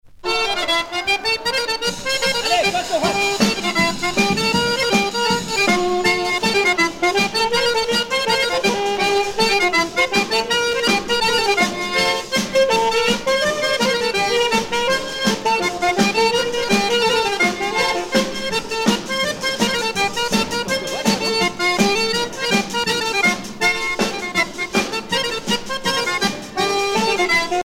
danse
Sonneurs de clarinette
Pièce musicale éditée